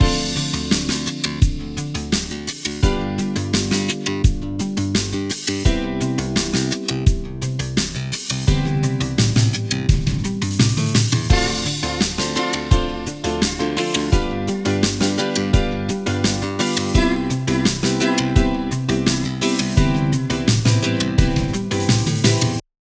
background music theme for your video.